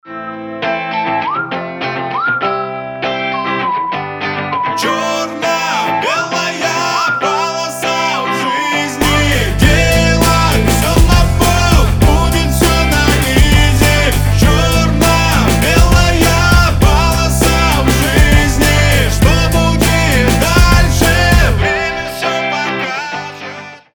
• Качество: 320, Stereo
позитивные
свист
вдохновляющие
воодушевляющие